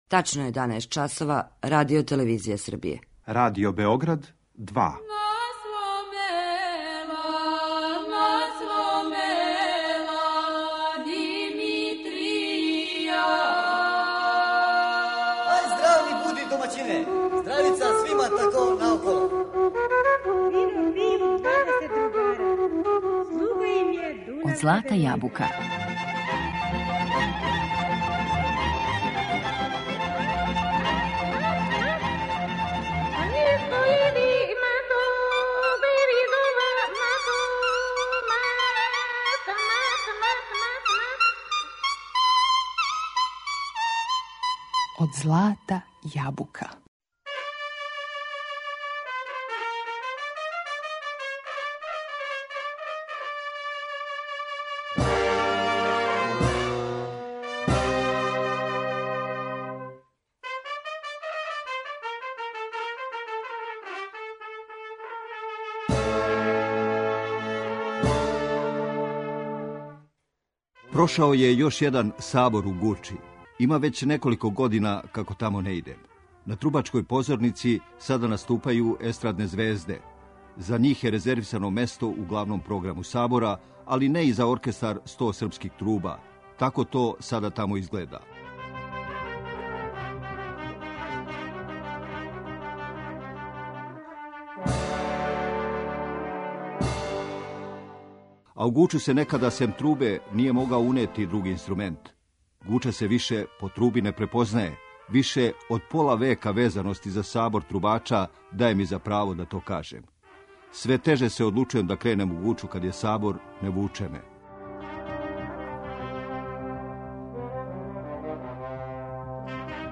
Како је некада изгледала ова највећа светковина у Западној Србији, а како изгледа данас, за данашњу емисију говори новинар